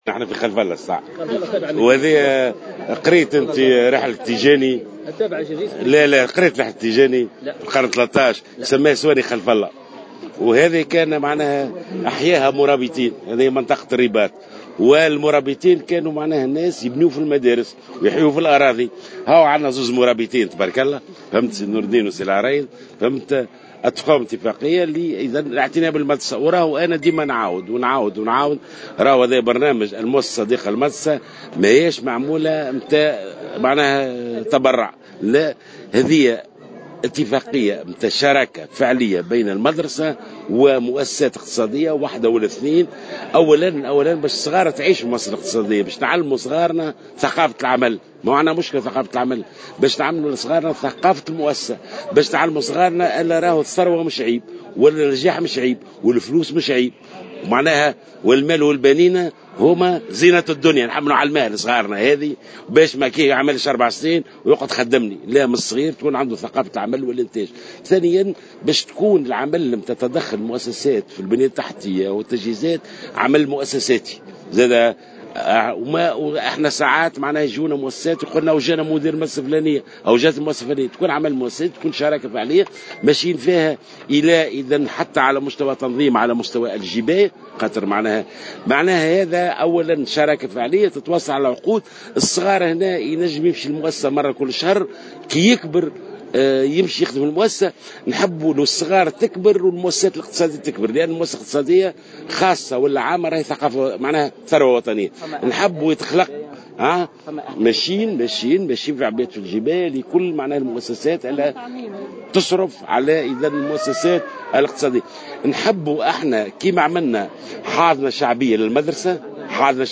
وصف وزير التربية ناجي جلول ممثلين اثنين عن مؤسسة اقتصادية للاعتناء بمدرسة في منطقة خلف الله من ولاية مدنين بـ "المرابطين"، على هامش زيارة أداها اليوم الخميس للمنطقة.